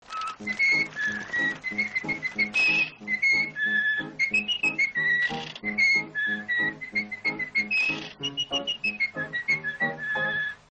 Whistle.mp3